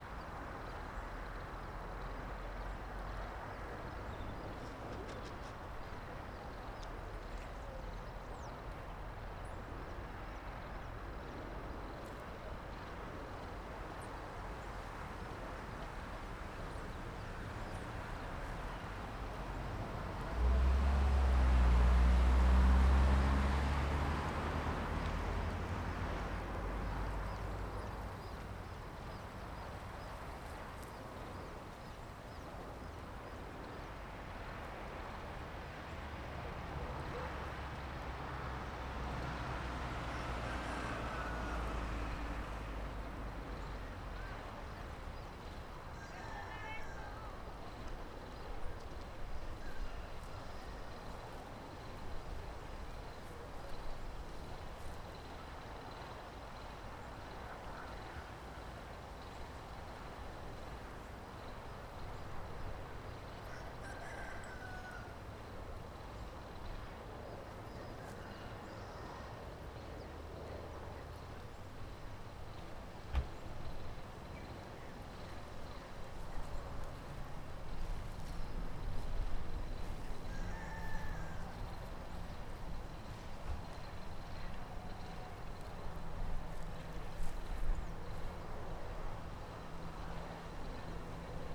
Arquivo de Porta de carro - Coleção Sonora do Cerrado